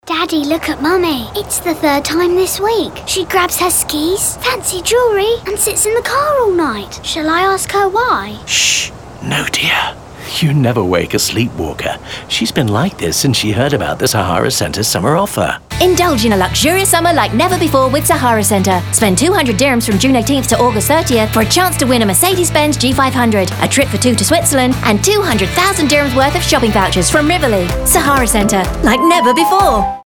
Sahara Centre Summer Campaign Radio Ad Print